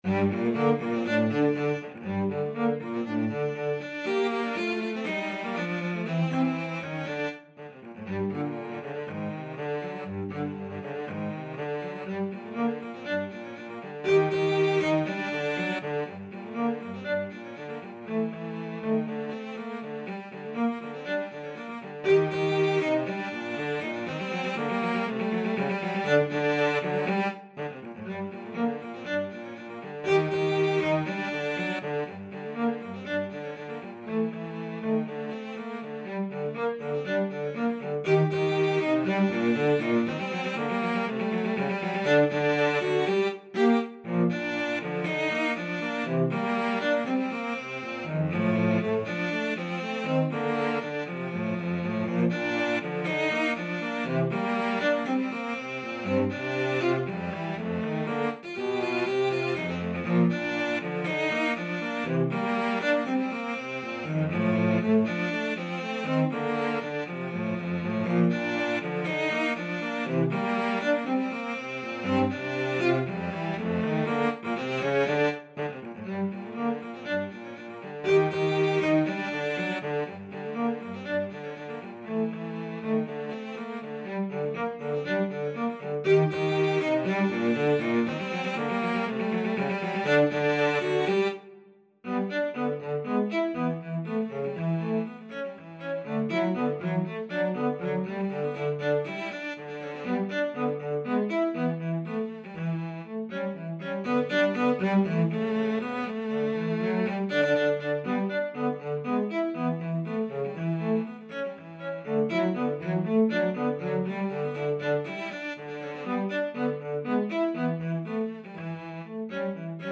Allegretto Misterioso = 120
2/4 (View more 2/4 Music)
Cello Duet  (View more Intermediate Cello Duet Music)
Classical (View more Classical Cello Duet Music)